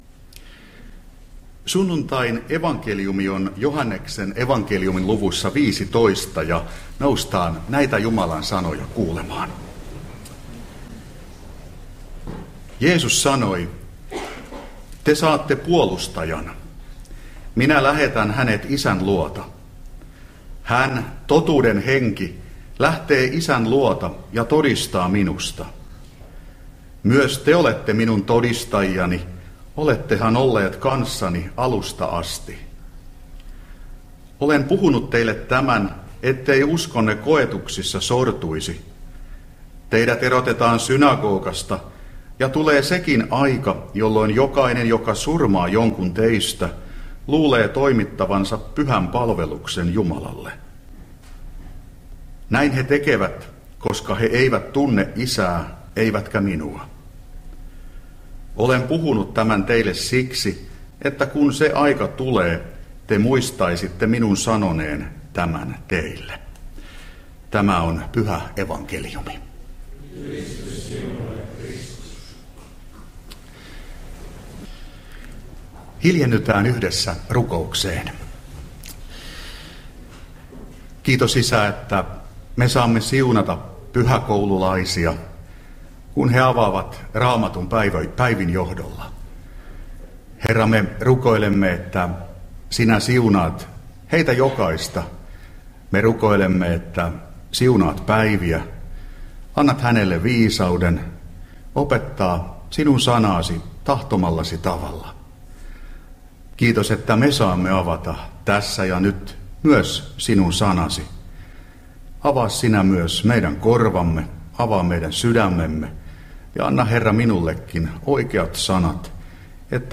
Sastamala